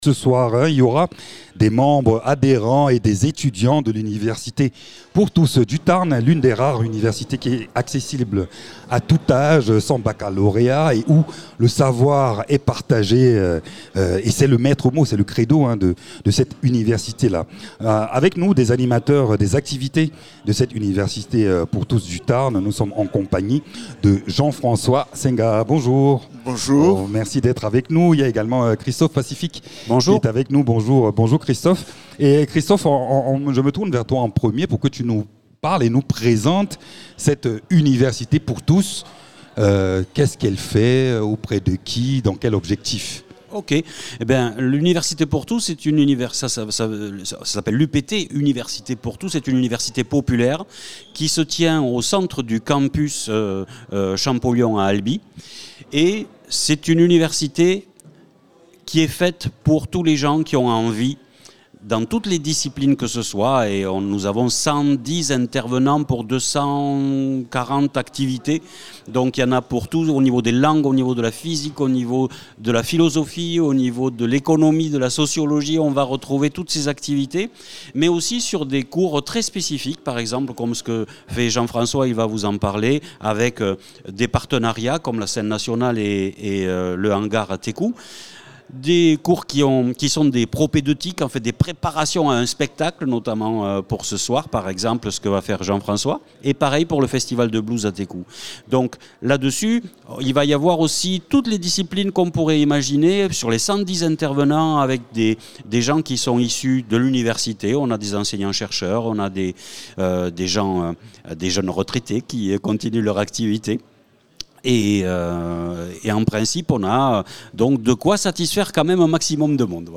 Présenté par